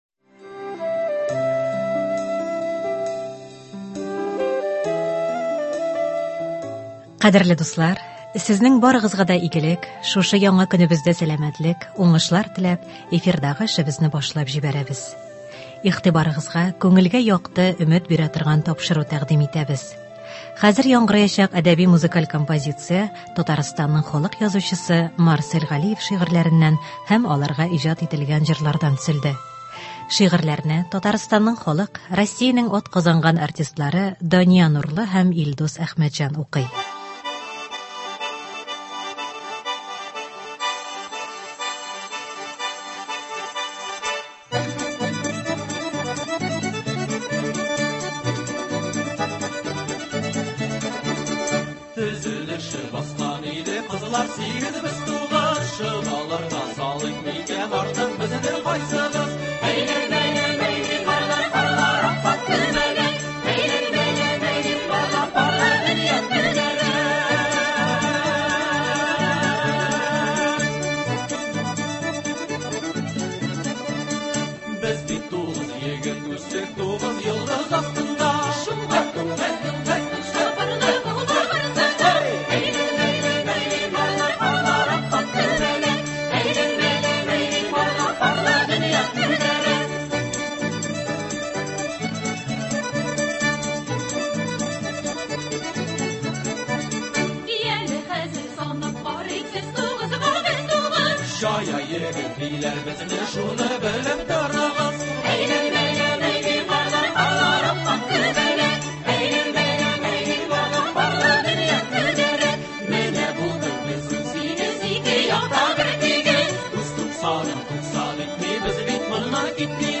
“Көзге моң”. Марсель Галиев әсәрләреннән әдәби-музыкаль композиция.
Әдәби-музыкаль композиция (22.09.23)